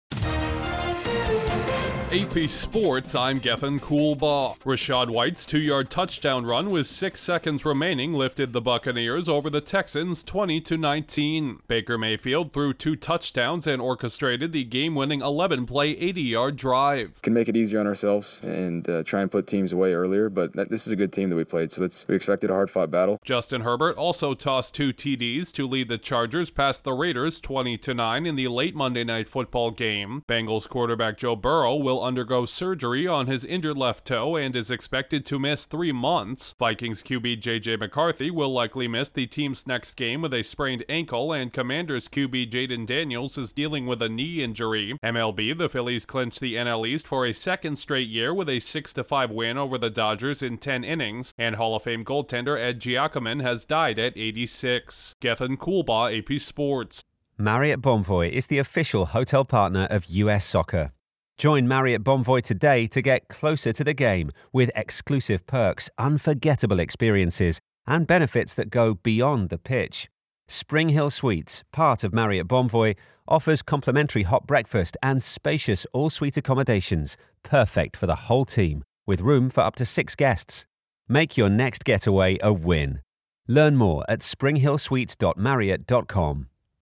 Tampa Bay rallies past Houston and the L.A. Chargers handle Las Vegas on Monday Night Football, a star quarterback and two other NFL signal callers are dealing with injuries, an extra-innings finish on the diamond in L.A. and a Hockey Hall of Fame goalie dies. Correspondent